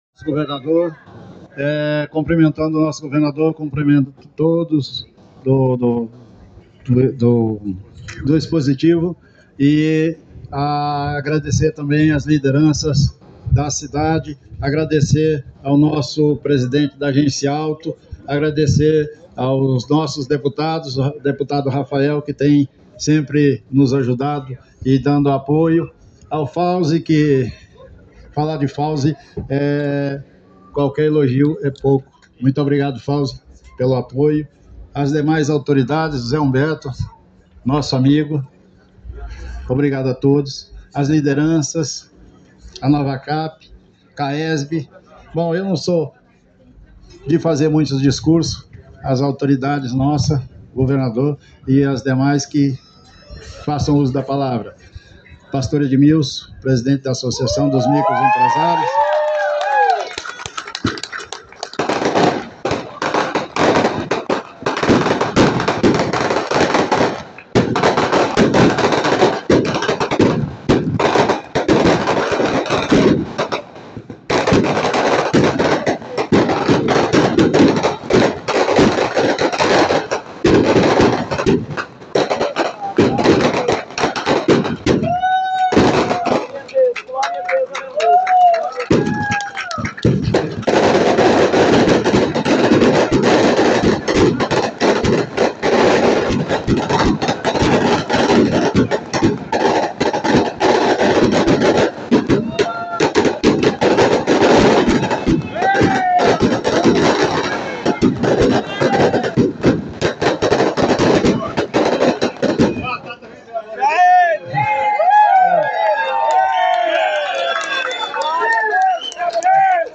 Discursos Marcantes
Na manhã deste sábado, a Cidade Estrutural foi palco de uma importante solenidade que contou com a presença de diversas autoridades e lideranças locais. A cerimônia marcou a inauguração de uma série de obras e melhorias na região, celebradas pelo administrador da cidade, Alceu Prestes, e pelo governador Ibaneis Rocha, entre outros.
Alceu Prestes, Administrador da Cidade Estrutural